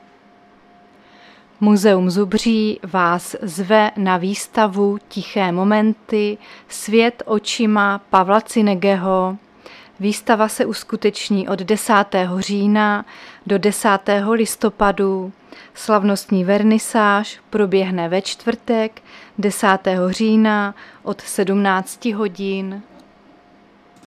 Záznam hlášení místního rozhlasu 10.10.2024
Zařazení: Rozhlas